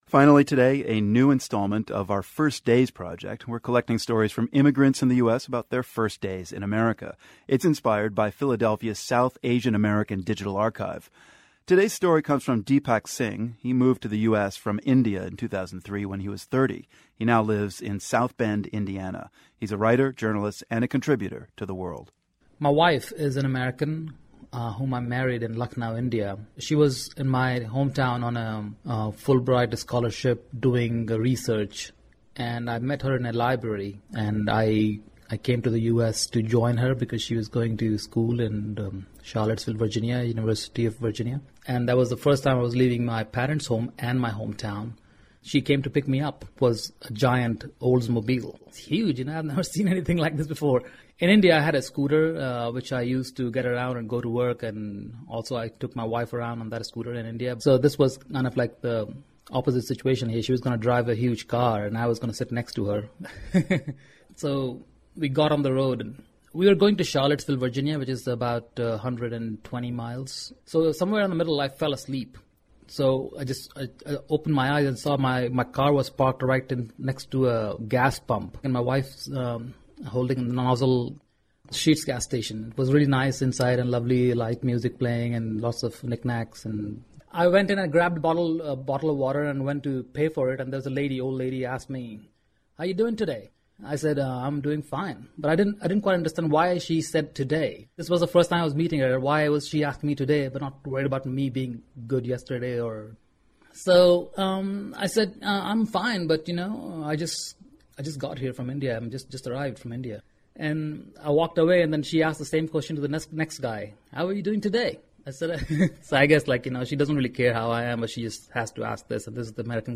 The Charlottesville Podcasting Network is proud to debut a new series of feature reports on the cultural and spiritual life of the South Asian community in Central Virginia.